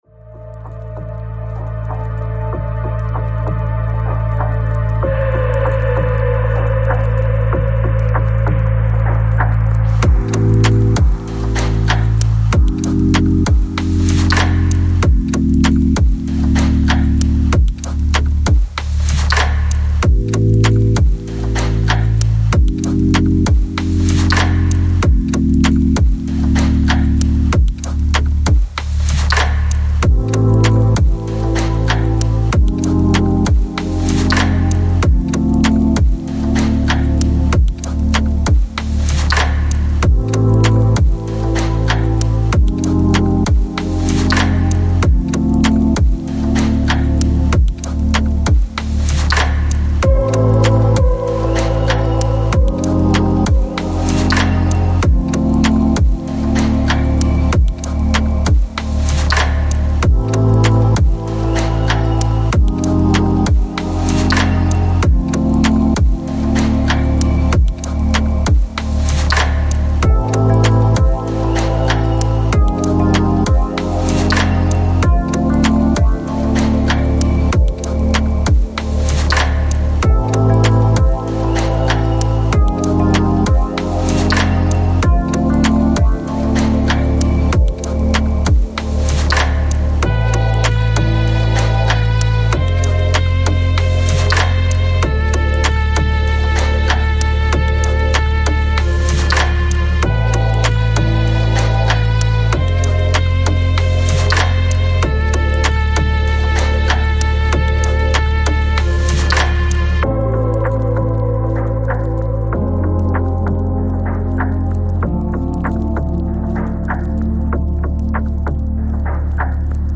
Lo-Fi, Lounge, Chillout